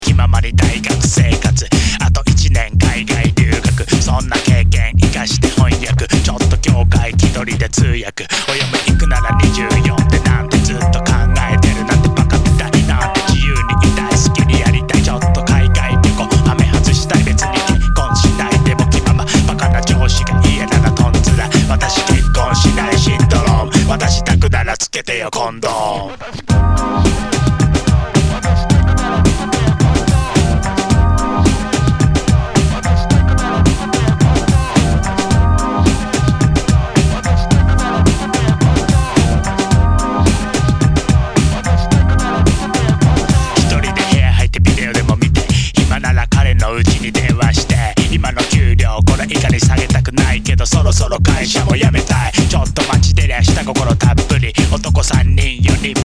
類別 饒舌、嘻哈
HIPHOP/R&B
全体にチリノイズが入ります